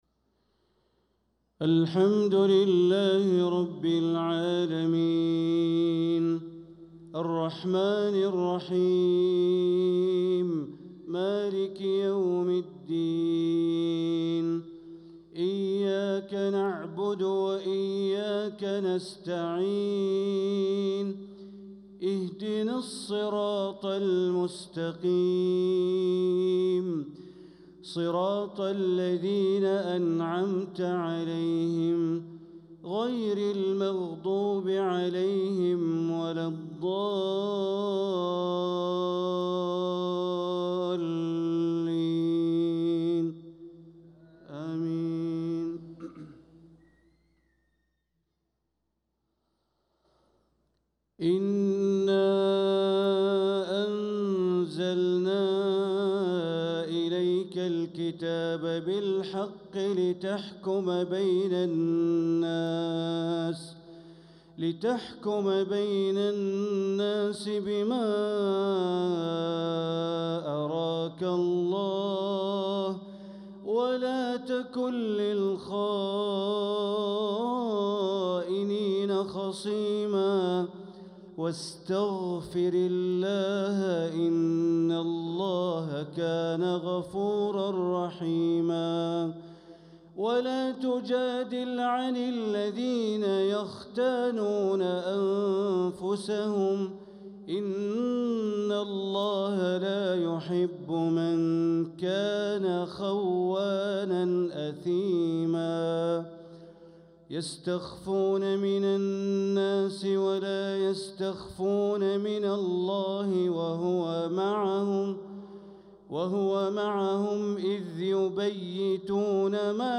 صلاة العشاء للقارئ بندر بليلة 3 صفر 1446 هـ
تِلَاوَات الْحَرَمَيْن .